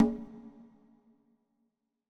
KIN Conga 1.wav